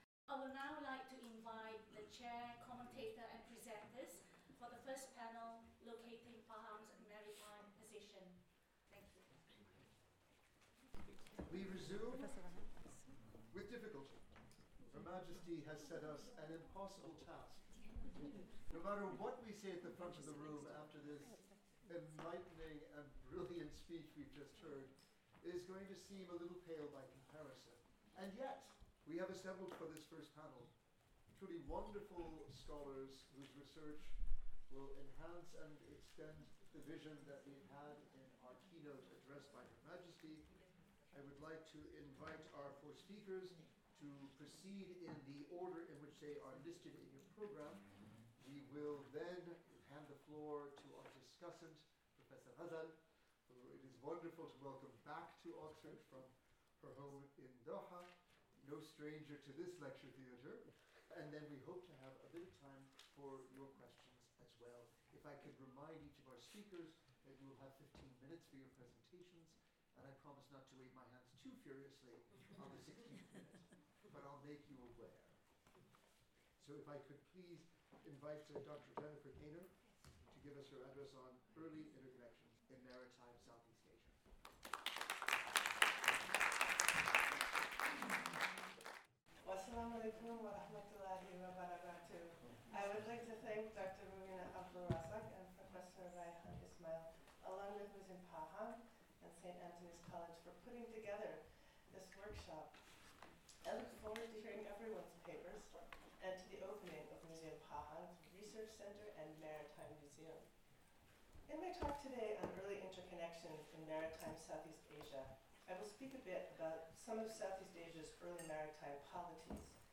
This talk describes some of the evidence for these precursor networks among seafaring people around and across the South China Sea, networks that connected places associated with speakers of Austronesian languages.